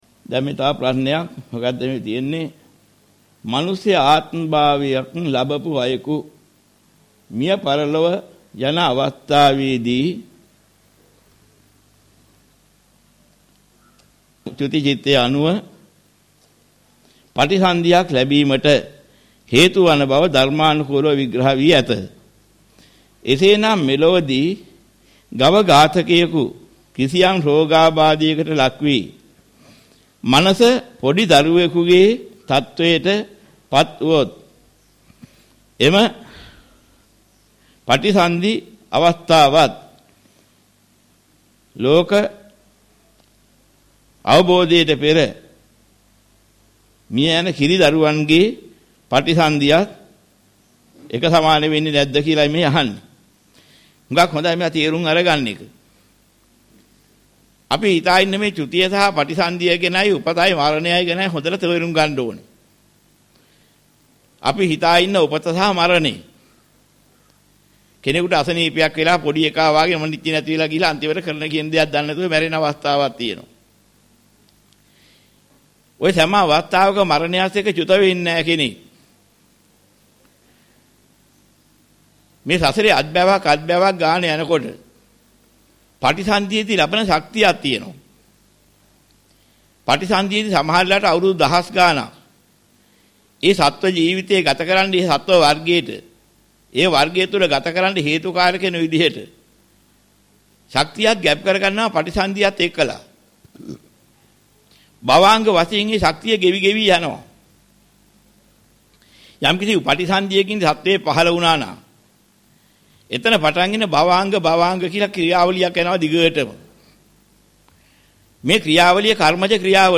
මෙම දේශනාවේ අඩංගු ගාථා හෝ සූත්‍ර කොටස්